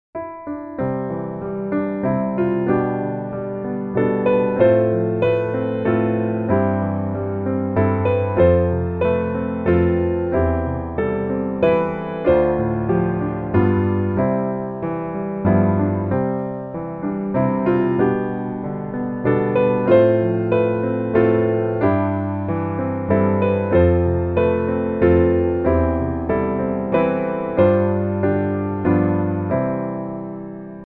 Traditional English carol
C Major